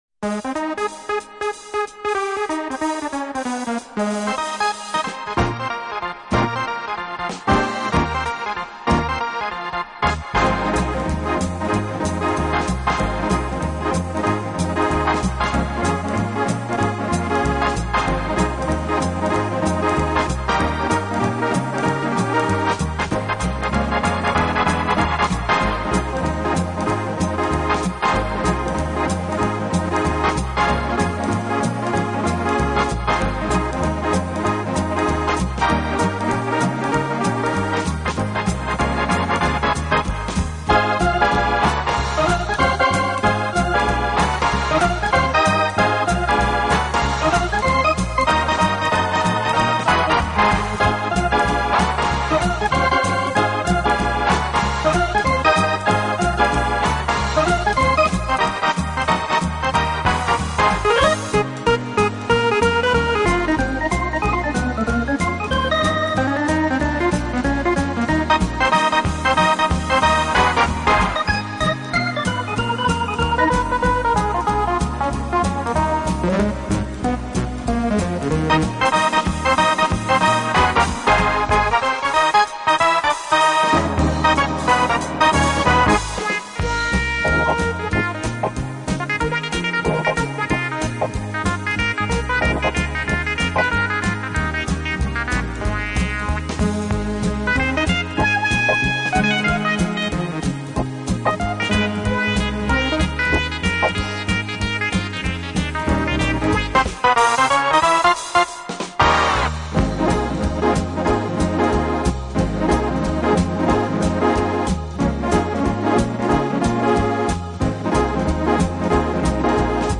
Genre:Electronic, Jazz
Style:Instrumental